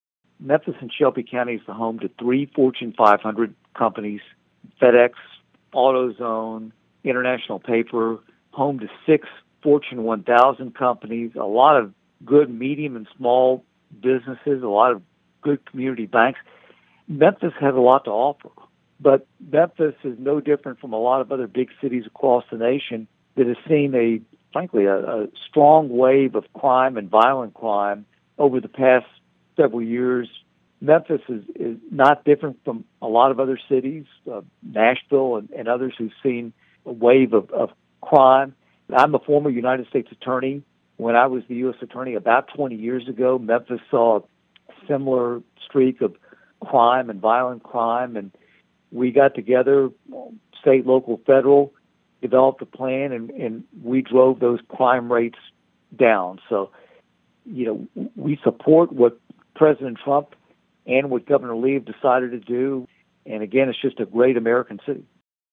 The Shelby County Republican said the issue of crime had to be addressed, because Memphis is a city that has a lot to offer.(AUDIO)